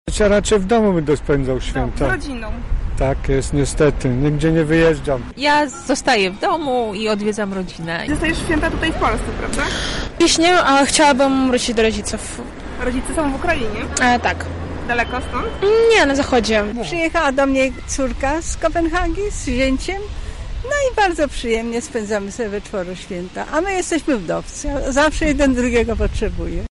Nasza reporterka zapytała przechodniów Krakowskiego Przedmieścia o plany na ten czas:
sonda